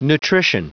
Prononciation du mot nutrition en anglais (fichier audio)